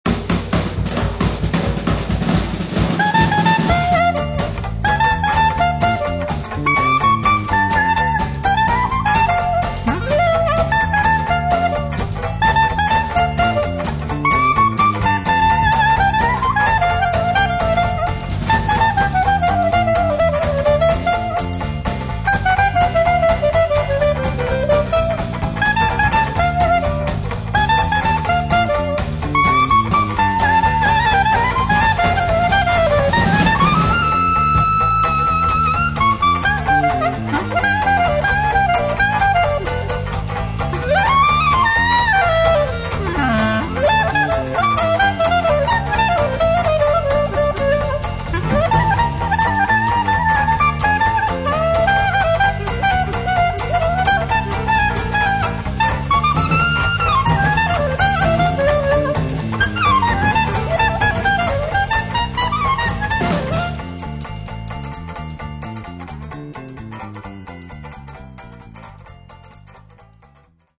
Trompete, Gesang
Posaune
Klarinette
Schlagzeug
Aufgenommen im Klangstudio LEYH, 69207 Sandhausen